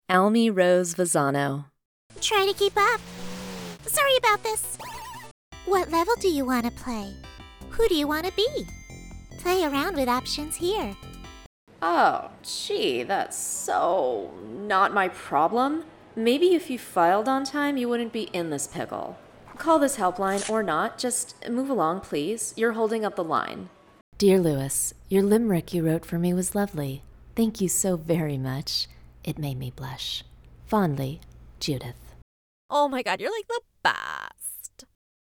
Animation & Shorts VO Reel